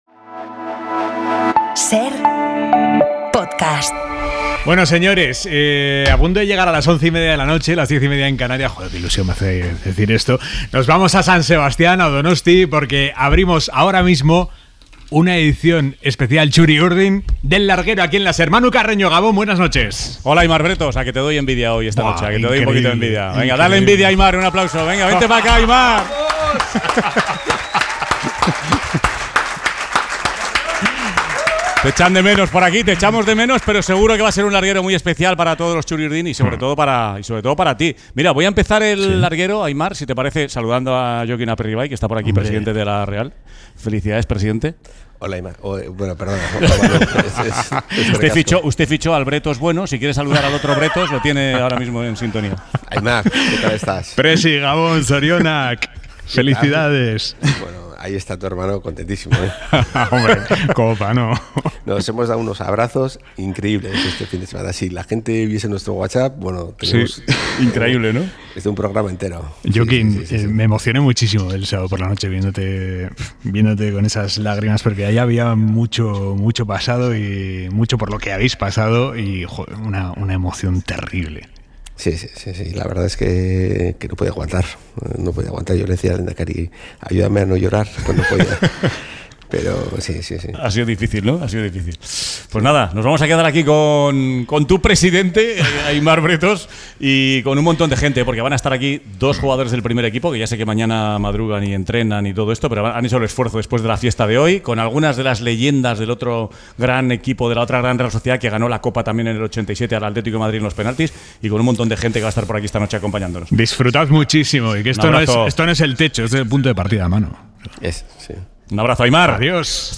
Especial del Larguero desde Donosti: entrevista a Pablo Martín, Carlos Soler y Jokin Aperribay